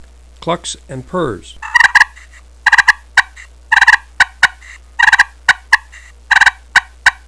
Listen to 7 seconds of clucks & purrs
ccpushpincluckspurrs7.wav